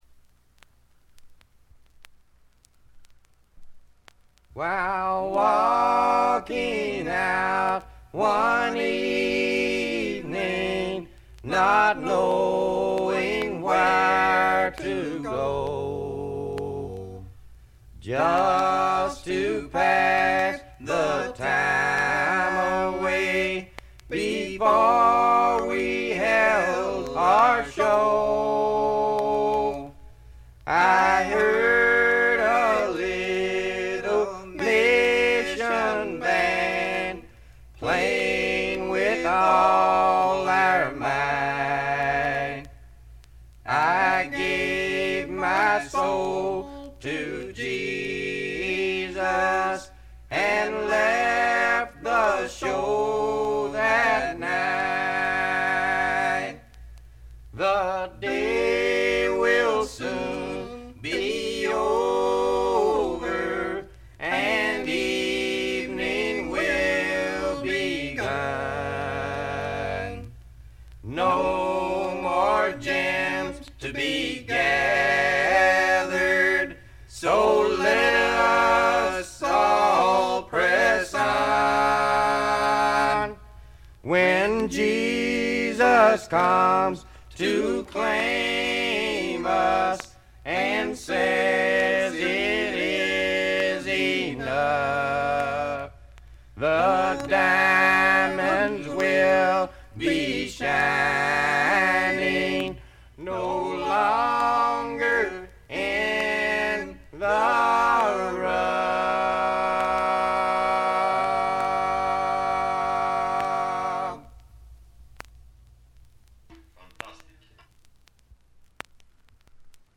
バックグラウンドノイズ、チリプチ多め大きめ。ところどころで散発的なプツ音。
アパラチアのマウンテンミュージックに根ざしたアコースティックなサウンドが心地よいです。
試聴曲は現品からの取り込み音源です。
mandolin, fiddle, banjo, vocals